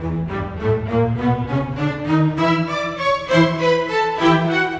Rock-Pop 20 Strings 01.wav